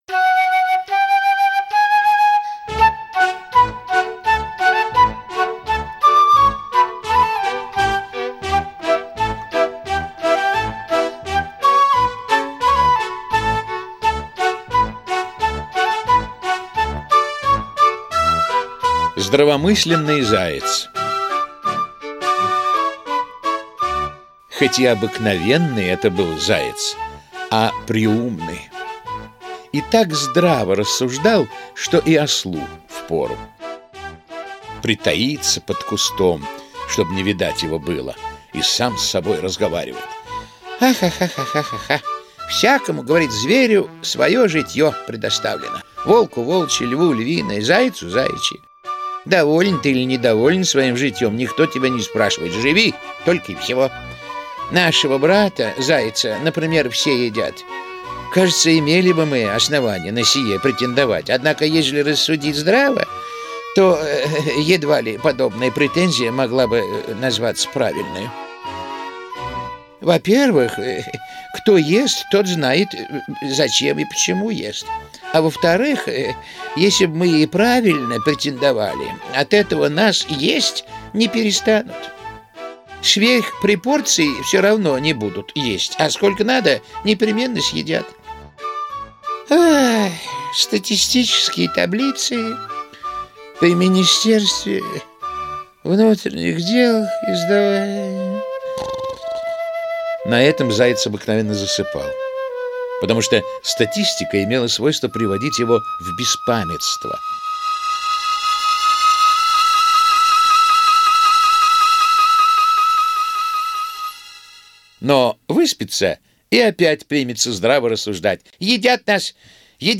Здравомысленный заяц - аудиосказка Михаила Салтыкова-Щедрина - слушать онлайн